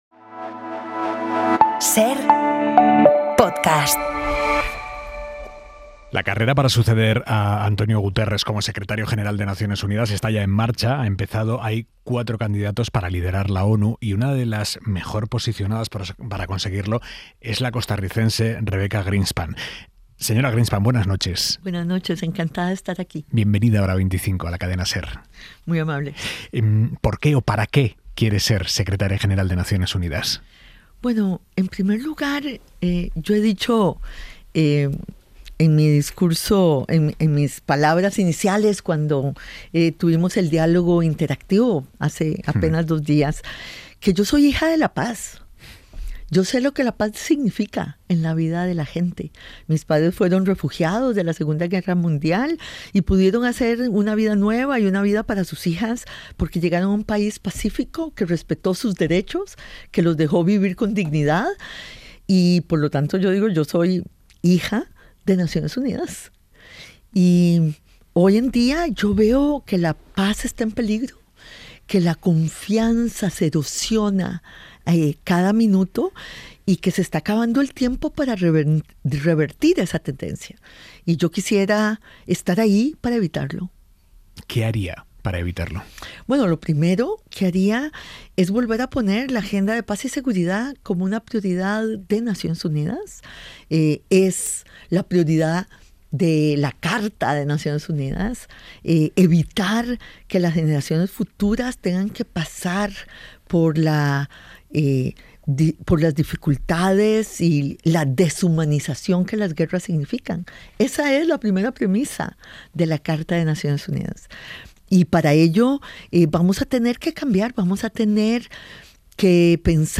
Aimar Bretos entrevista a Rebeca Grynspan, candidata a secretaria general de la ONU.